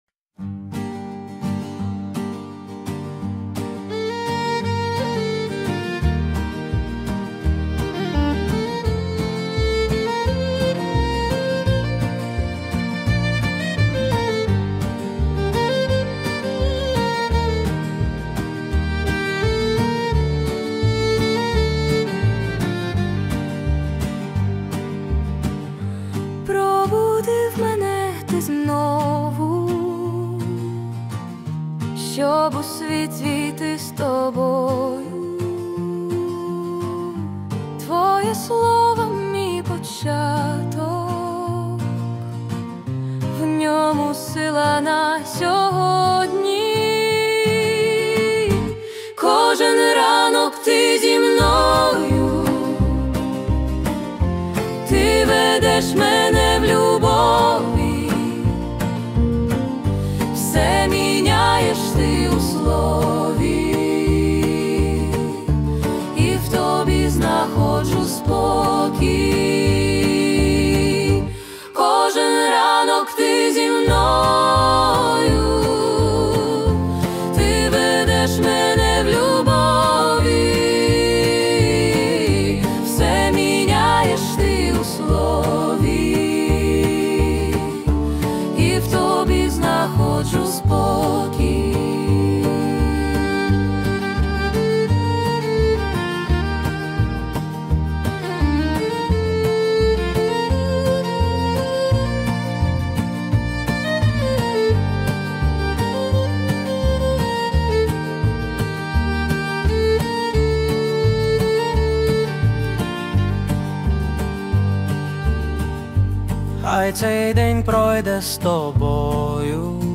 песня ai
7805 просмотров 3989 прослушиваний 1656 скачиваний BPM: 167